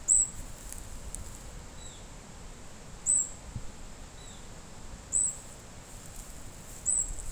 Carpintero Dorado Gris (Colaptes rubiginosus)
Nombre en inglés: Golden-olive Woodpecker
Condición: Silvestre
Certeza: Vocalización Grabada